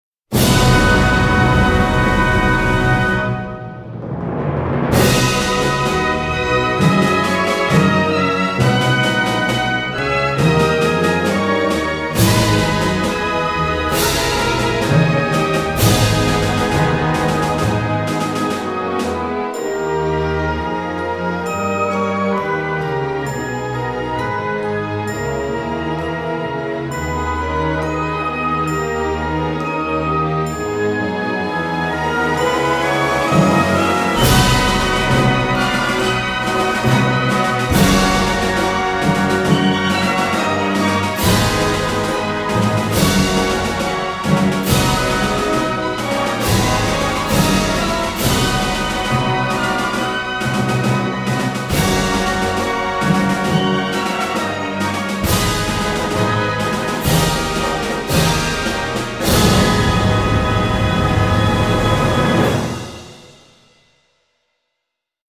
Торжественная мелодия и слова патриотической песни \
инструментальное исполнение